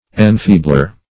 Enfeebler \En*fee"bler\, n. One who, or that which, weakens or makes feeble.